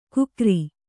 ♪ kukri